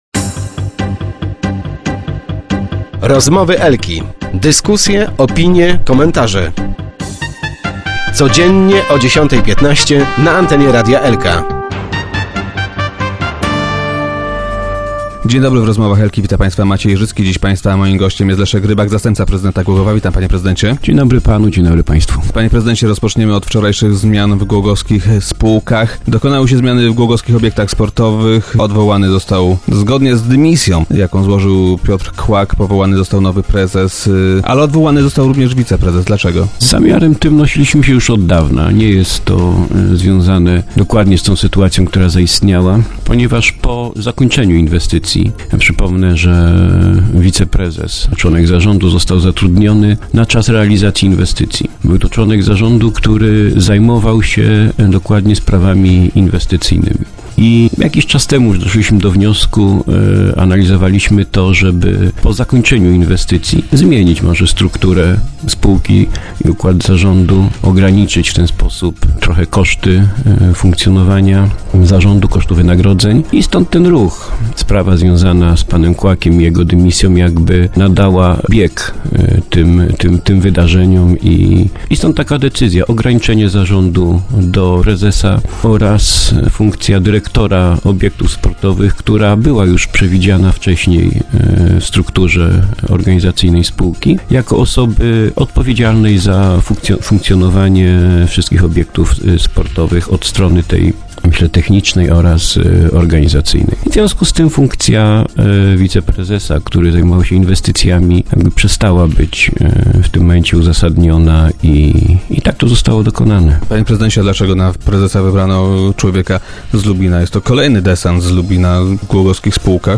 Jak powiedział Leszek Rybak, zastępca prezydenta miasta, który dziś był gościem Rozmów Elki, przyspieszyło to planowane zmiany w zarządzie spółki.